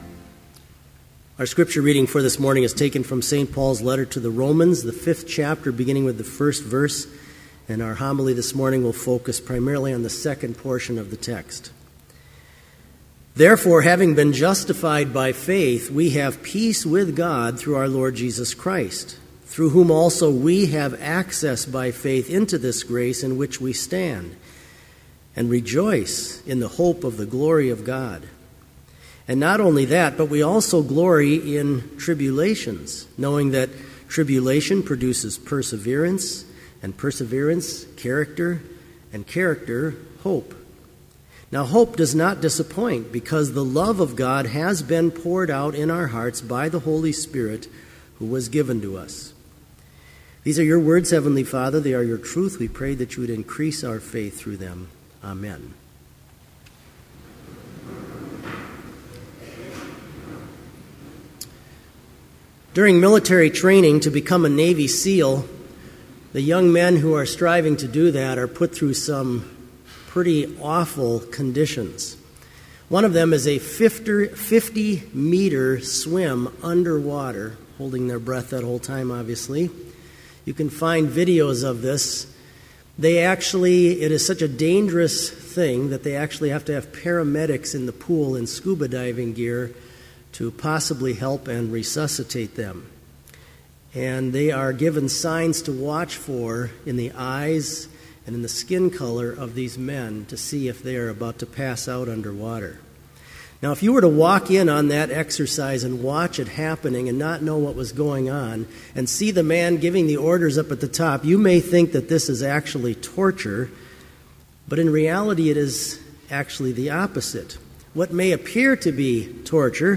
Complete Service
Order of Service
• Hymn 539, vv. 1-5, Jerusalem, My Happy Home
• Homily
• Postlude – Chapel Brass
This Chapel Service was held in Trinity Chapel at Bethany Lutheran College on Friday, November 16, 2012, at 10 a.m. Page and hymn numbers are from the Evangelical Lutheran Hymnary.